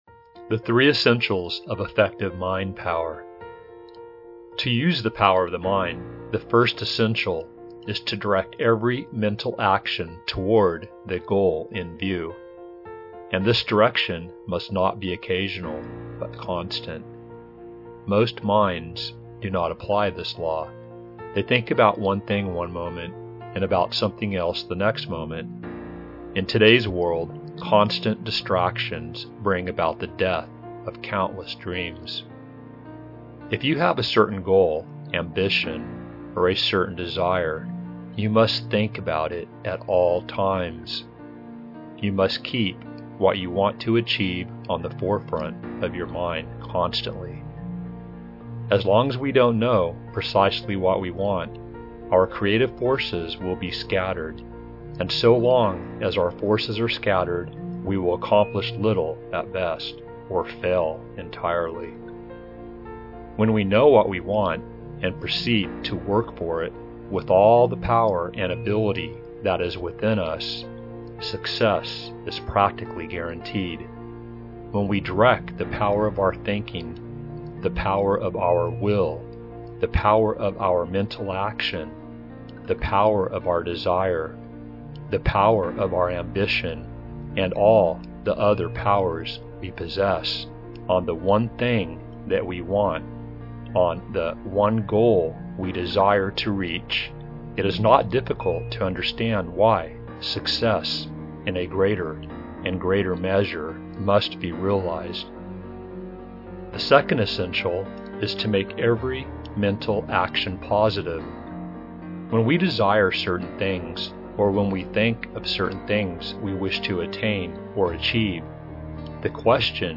The 3 Essentials of Mind Power Motitation is 21:15 minutes long with the message or “thought code” being repeated numerous times.